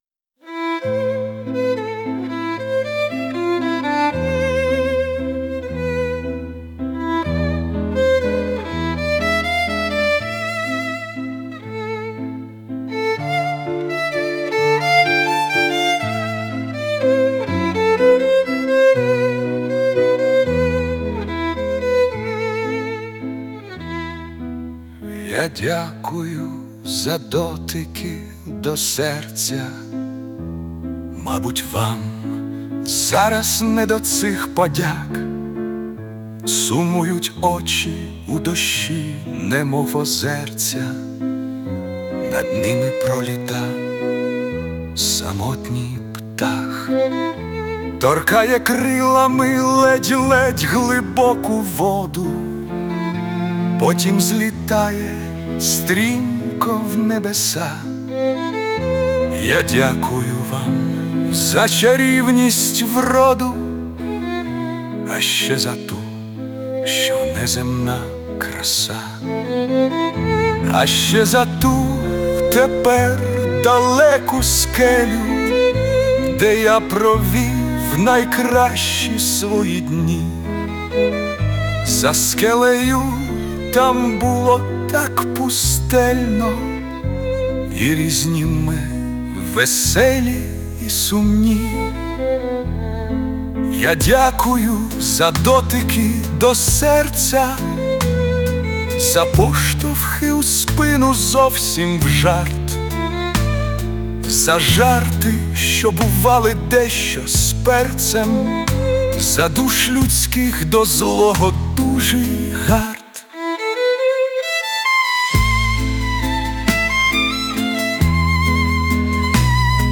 Музичне прочитання з допомогою ШІ
12 12 17 Душа сумує,із скрипкою плаче...зворушливо.. 17